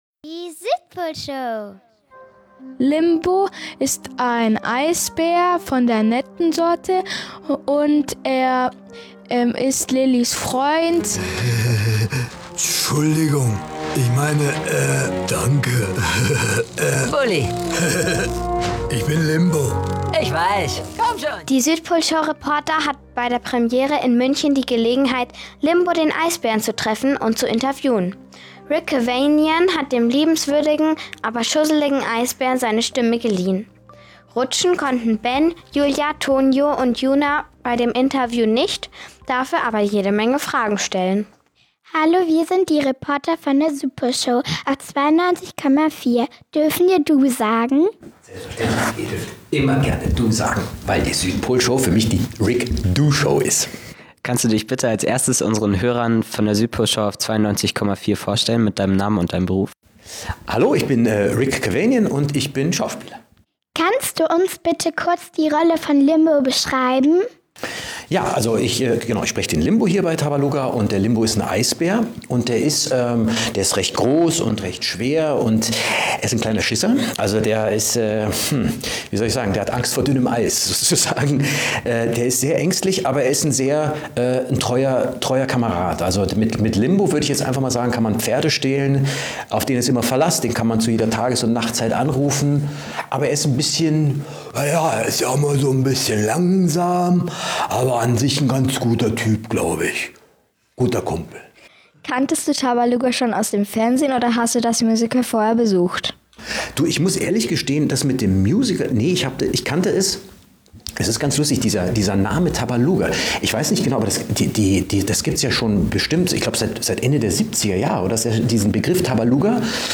Interview mit Rick Kavanian ~ Südpolshow | Radio Feierwerk 92,4 Podcast
Die Südpolreporter waren bei der Premiere und haben Rick zu seiner Synchronrolle im Tabaluga Film befragt. Die Rechte der Film O-Töne liegen bei Sony Pictures Entertainment – DVD „Tabaluga".